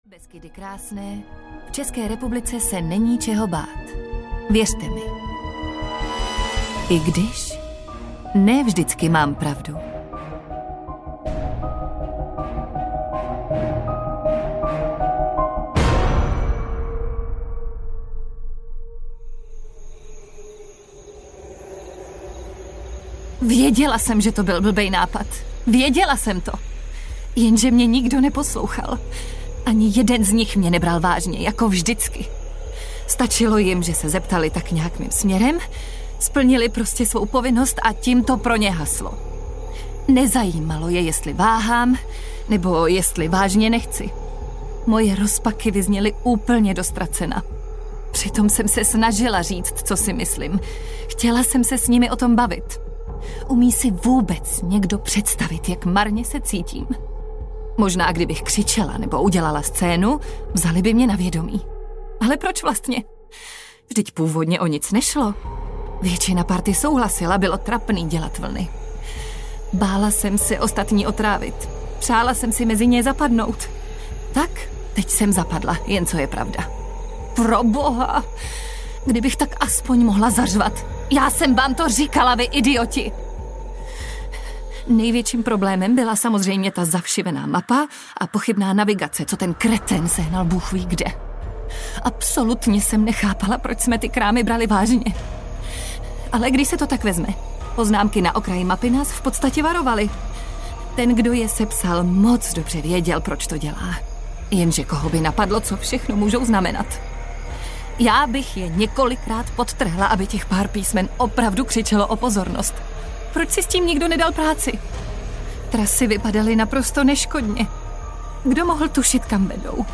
Odbočka v lesích audiokniha
Ukázka z knihy